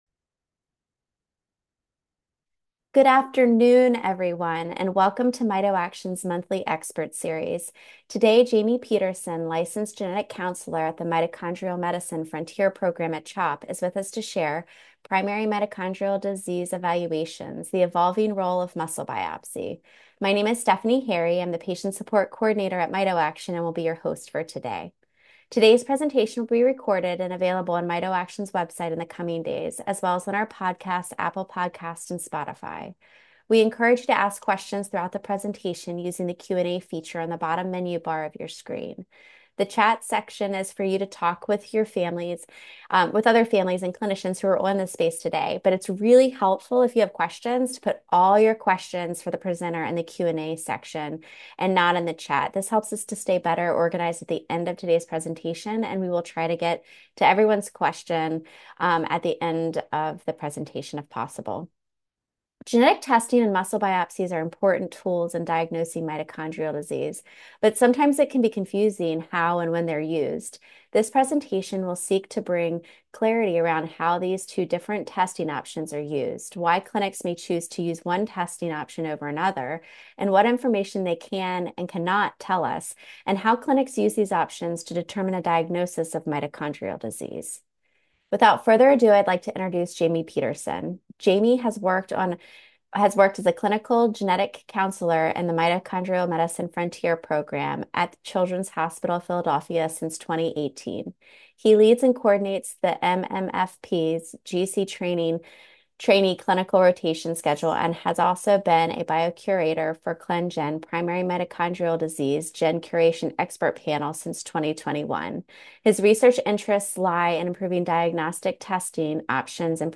Genetic testing and muscle biopsies are important tools in diagnosing mitochondrial disease, but sometimes it can be confusing how and when they are used. This presentation will seek to bring clarity around how these two different testing options are used, why clinics may choose to use one testing option over another, what information they can/cannot tell us, and how clinics use these options to determine a diagnosis of mitochondrial disease.